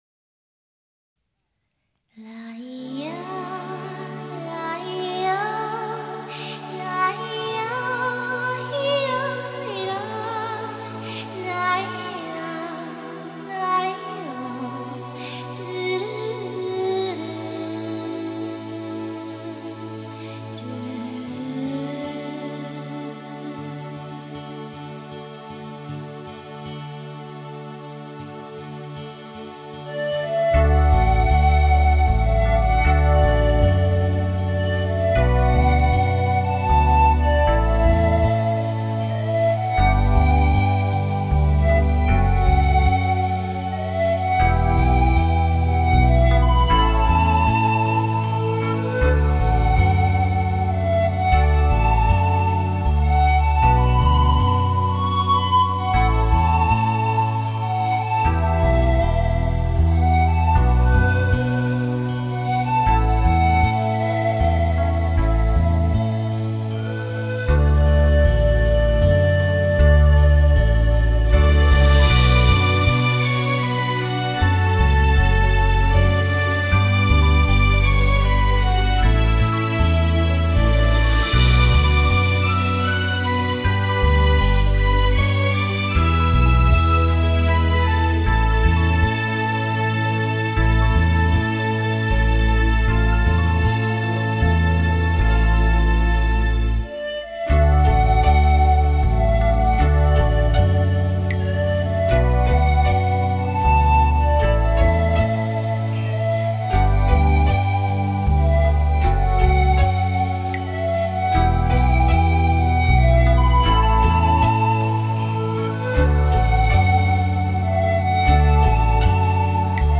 2周前 纯音乐 5